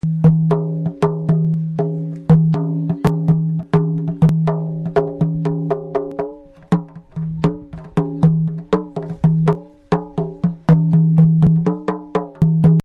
Création de Percussions en argile
Kanjra (167 Ko)